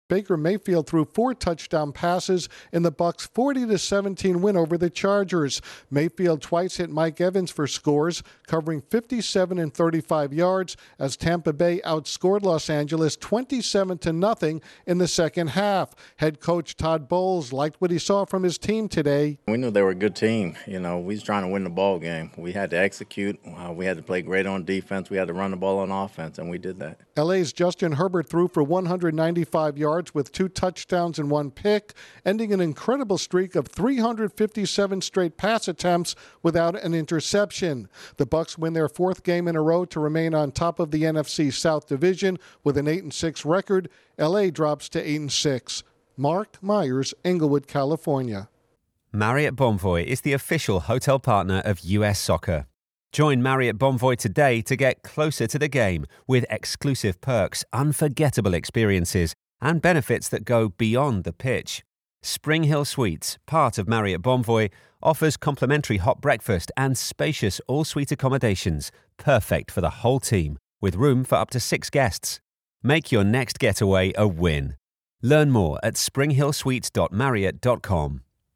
The Buccaneers strengthen their hold on a division title berth. Correspondent